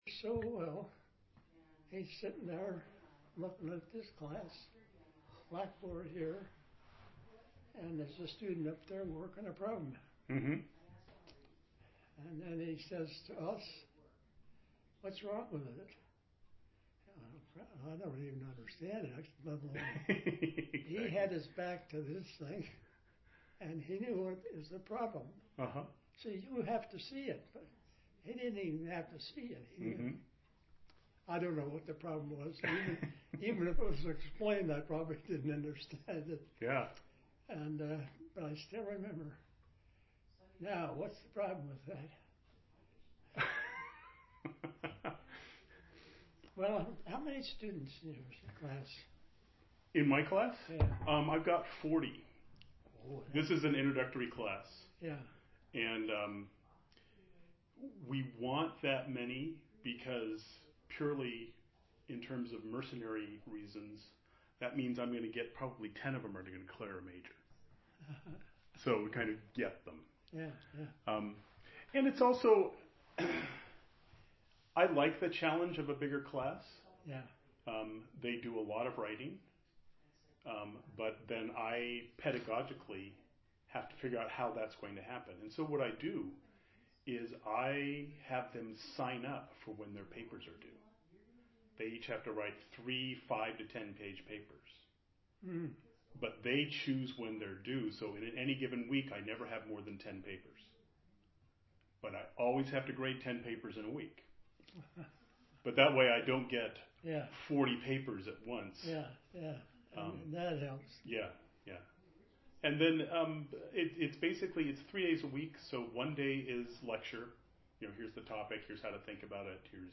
His voice is relatively faint and thin compared to earlier recordings.